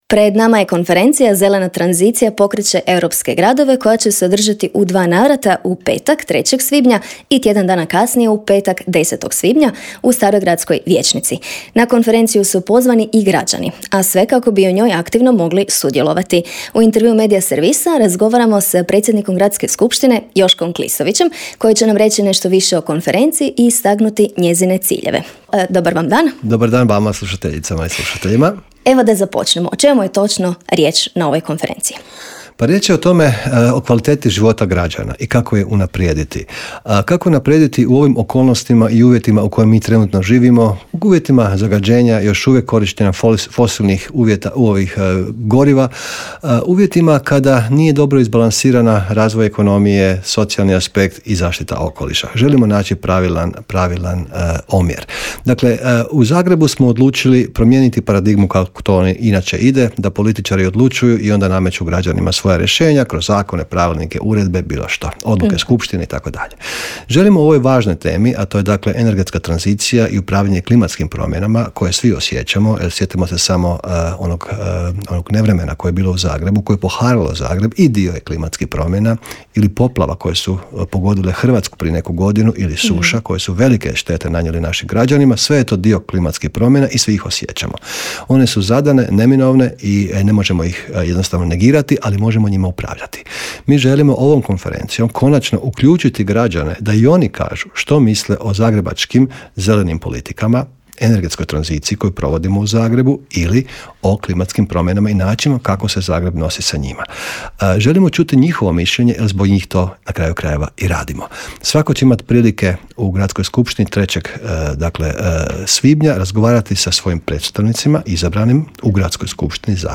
ZAGREB - Predsjednik zagrebačke Gradske skupštine, Joško Klisović, u Intervjuu Media servisa govorio je o konferenciji pod nazivom "Zelena tranzicija...